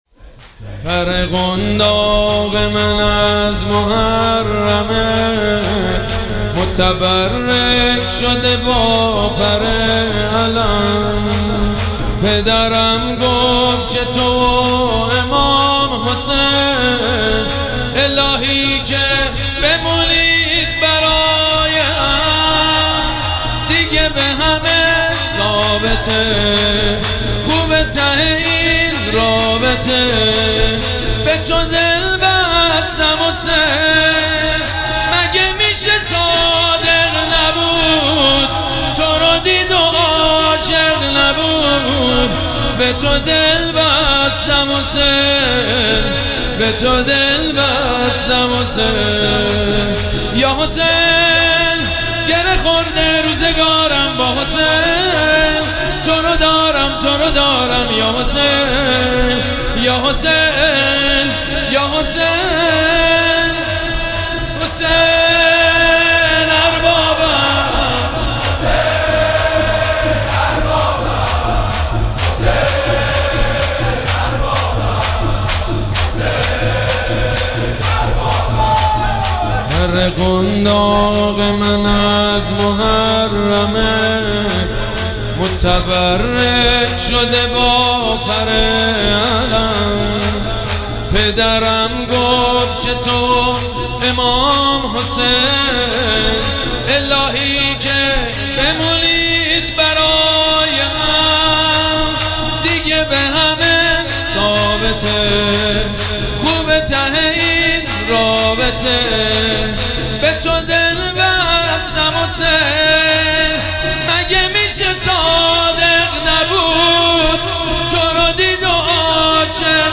محفل عزاداری شب چهارم محرم
شور